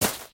Divergent / mods / Footsies / gamedata / sounds / material / human / step / gravel2.ogg
gravel2.ogg